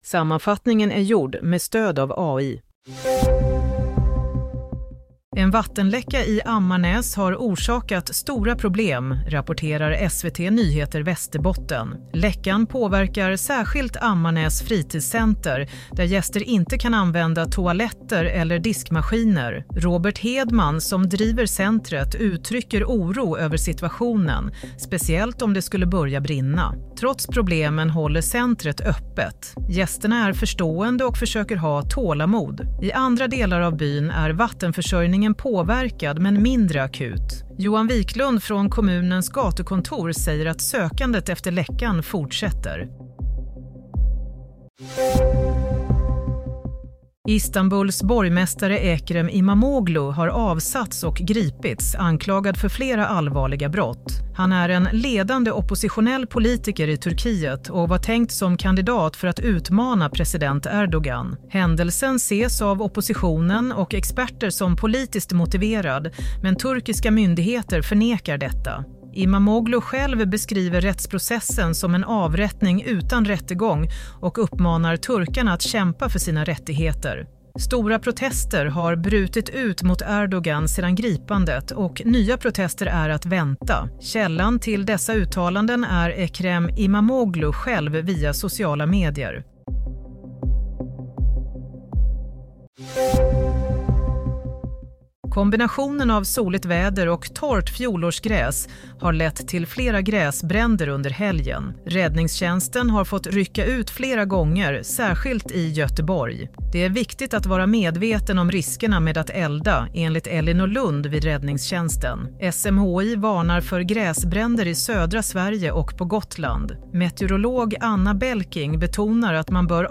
Play - Nyhetssammanfattning 23 mars